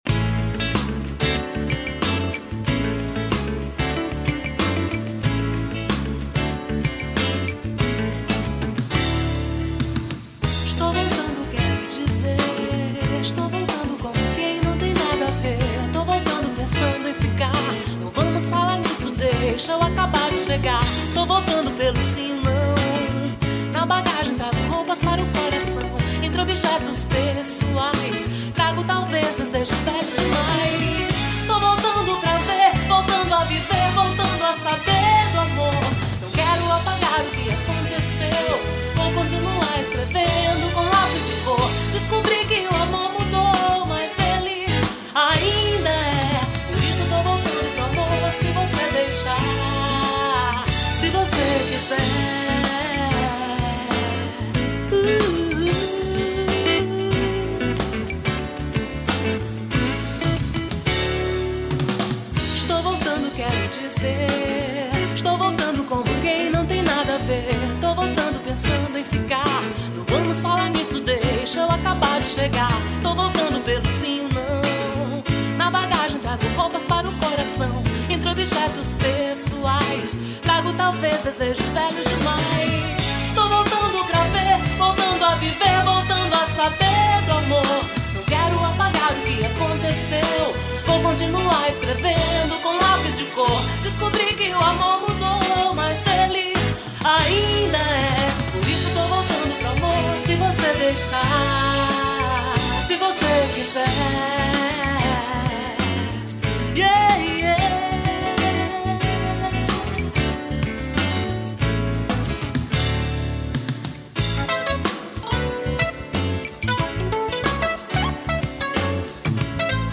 1706   03:09:00   Faixa:     Rock Nacional